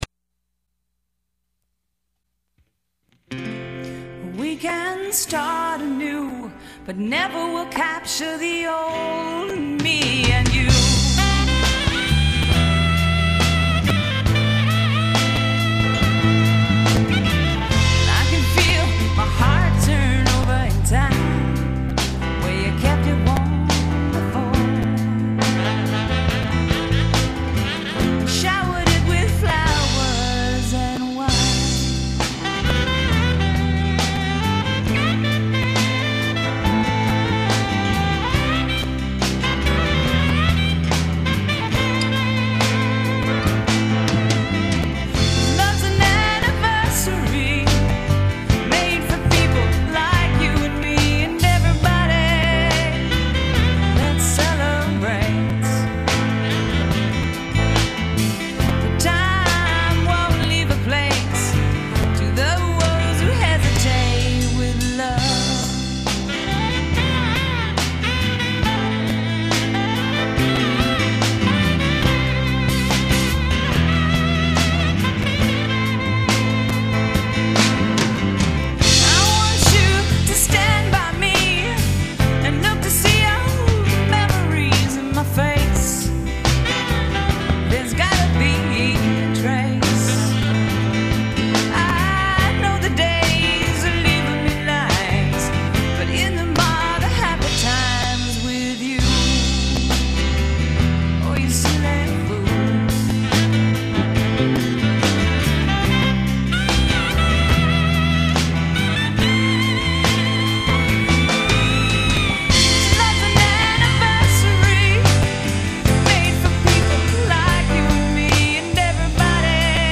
moderate acoustic ballad